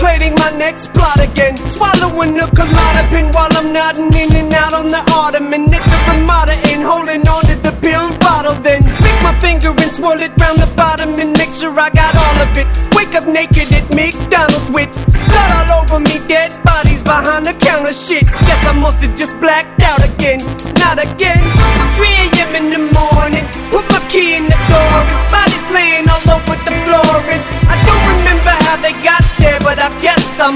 "enPreferredTerm" => "Hip hop, rap"